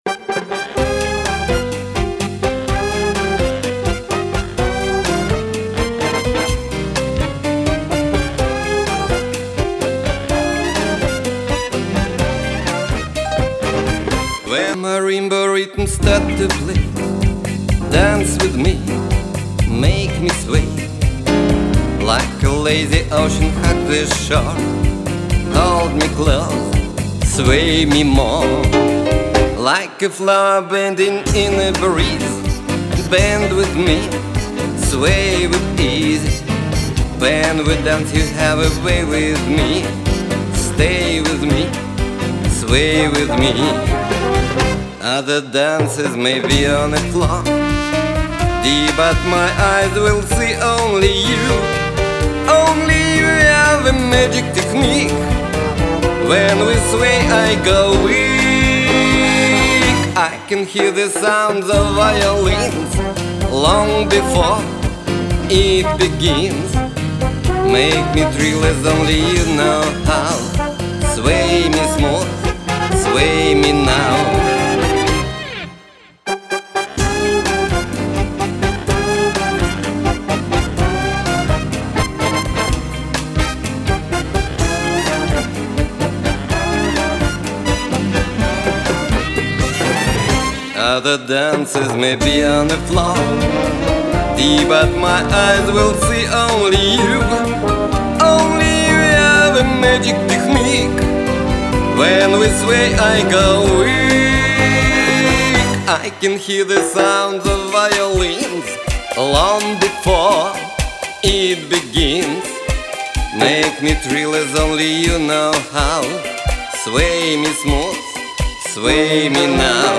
И голос слышно теперь, как он у тебя хорош! klass
Я же пишу с пульта получая сразу сведенный результат.